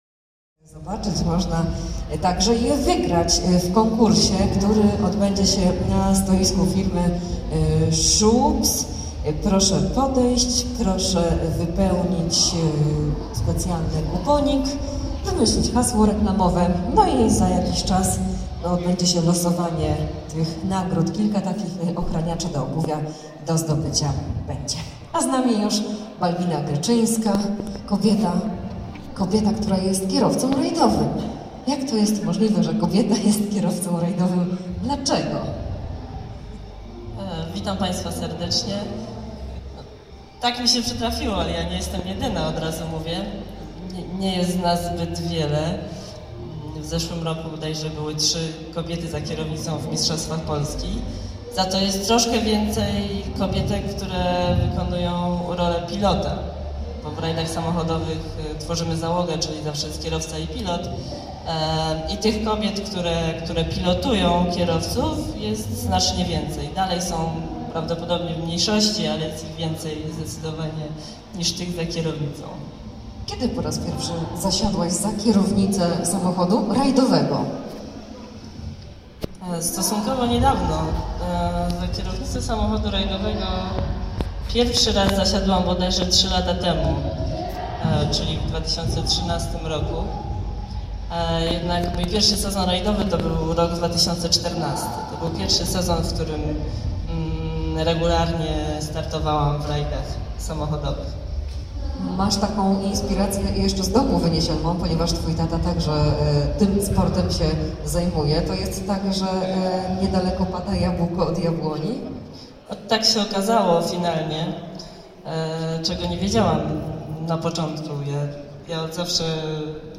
Wywiad
„Kobieta w męskim świecie”. Targi „Kobiety w Centrum” 12-13 marca Opole. https